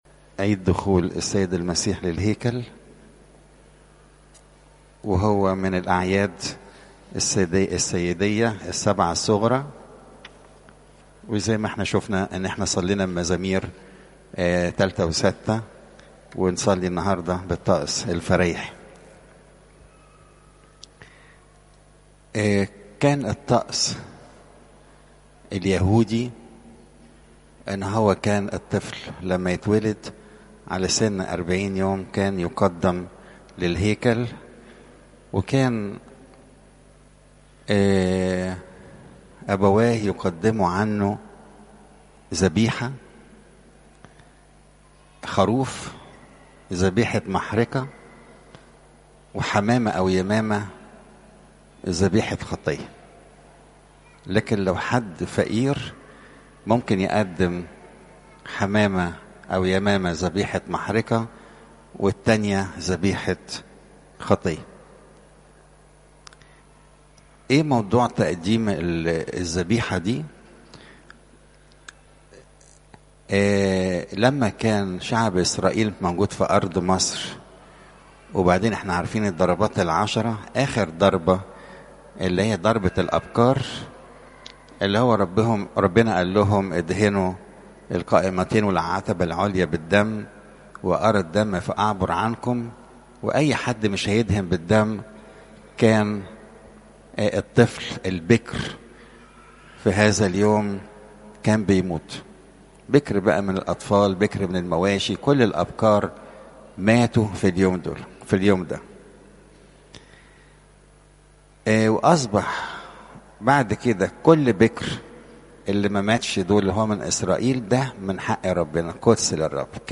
عظات المناسبات عيد دخول المسيح الهيكل